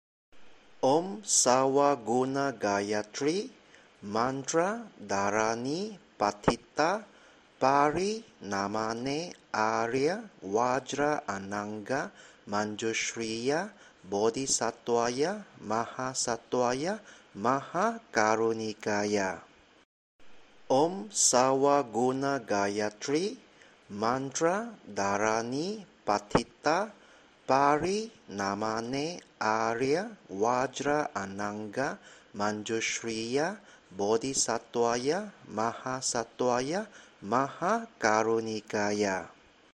以下音頻是末學的錄音，末學的念誦方式未必適合每一個人口味，師兄們可以參考其他師兄的念誦方式。